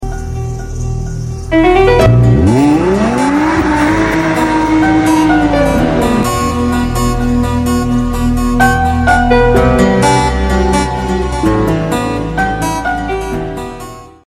v10 sound❤‍🔥